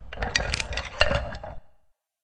PixelPerfectionCE/assets/minecraft/sounds/mob/witherskeleton/say3.ogg at mc116